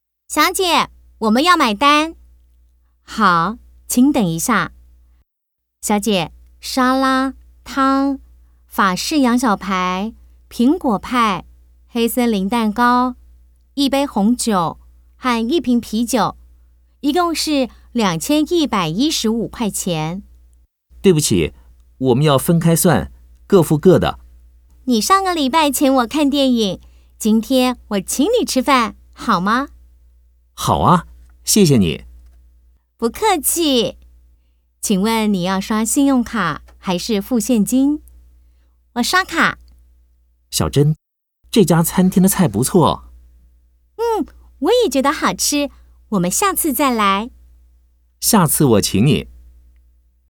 L7-1 Dialogue B.mp3